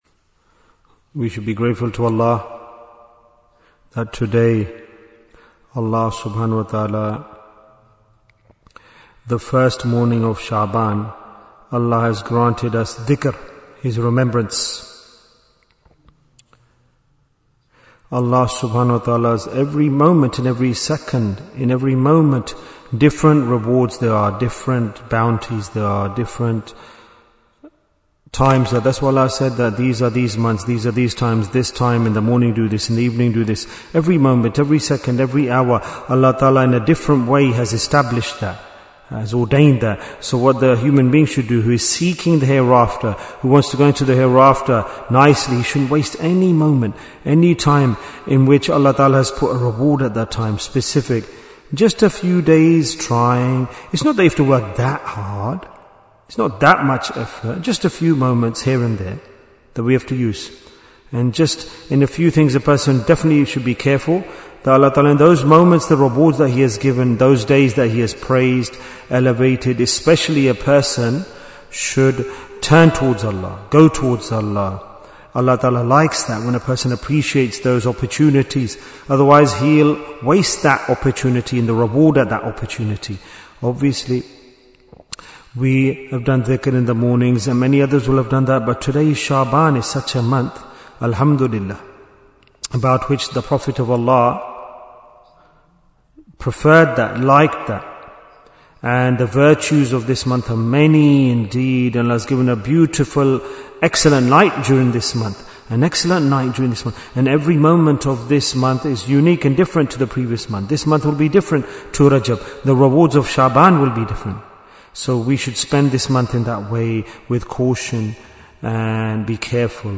Why is Tazkiyyah Important? - Part 8 Bayan, 13 minutes20th January, 2026